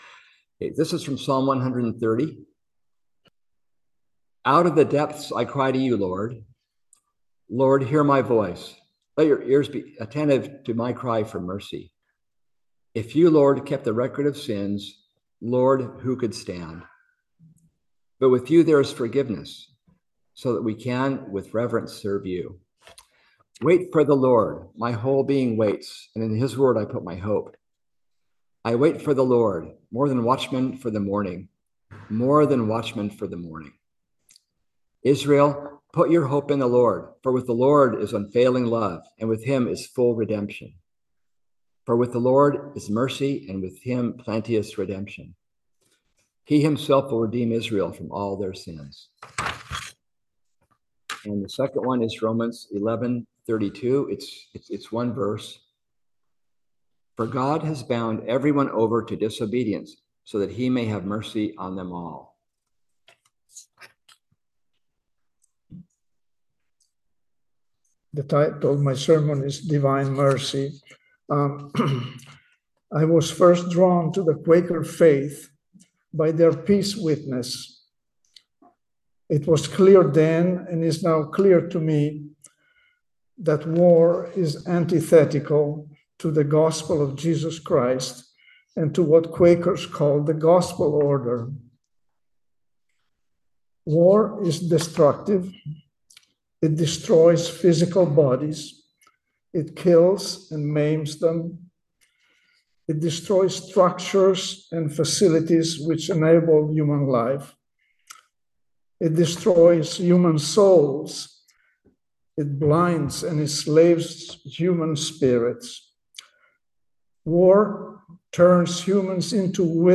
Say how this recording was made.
Listen to the most recent message from Sunday worship at Berkeley Friends Church, “Divine Mercy.”